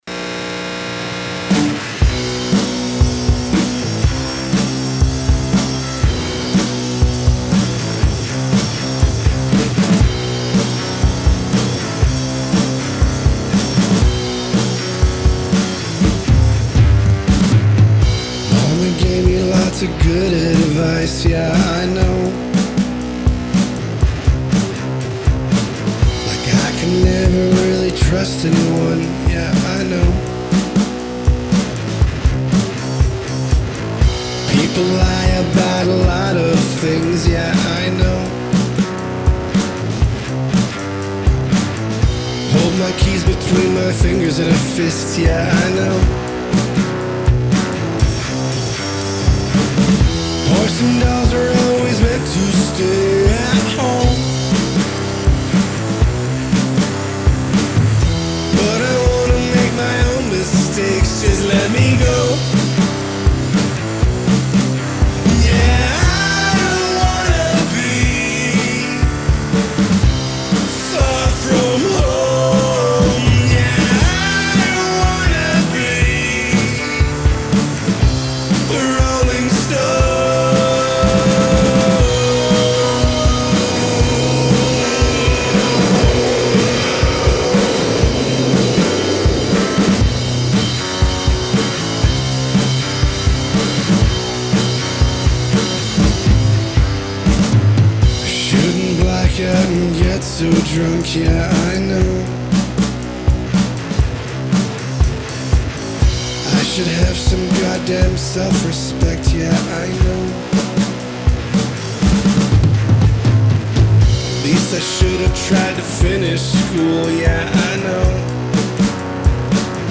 gutterfuzz duo blending raw DIY grunge
bass
drums